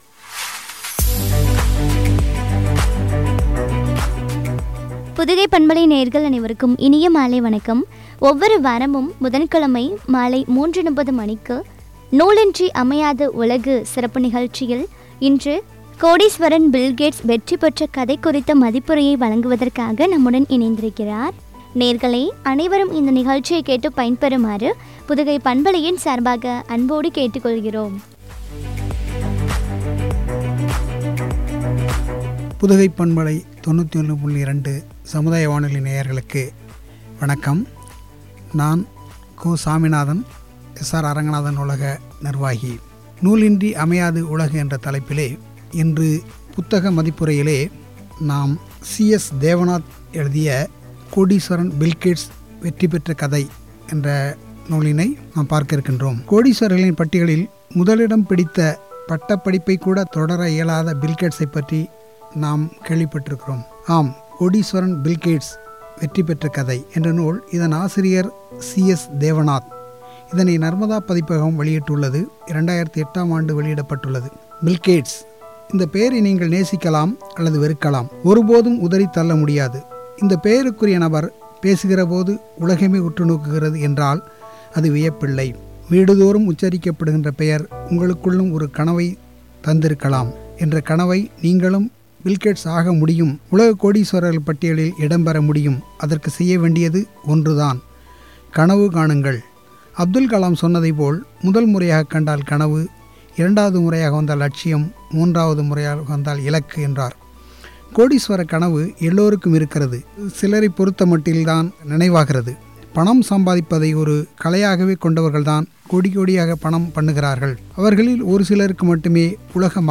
கோடீஸ்வரன் பில் கேட்ஸ் வெற்றி பெற்ற கதை” புத்தக மதிப்புரை (பகுதி -06)